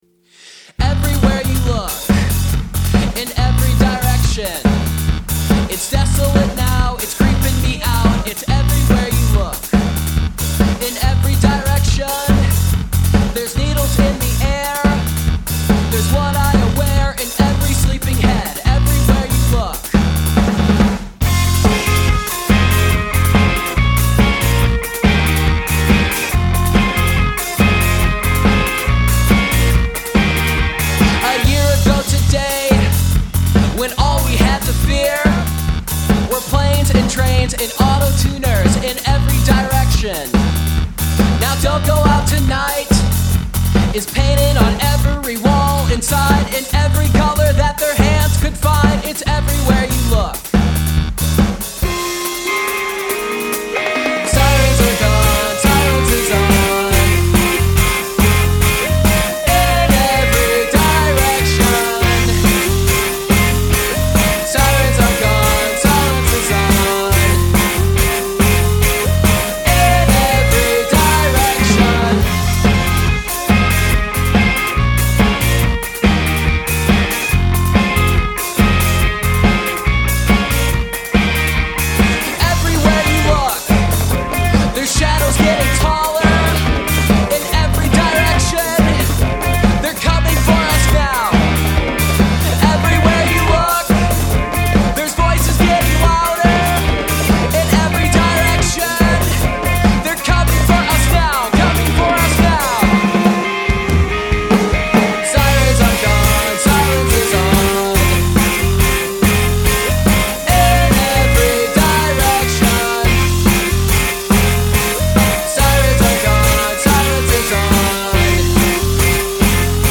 St. Louis-based quartet’s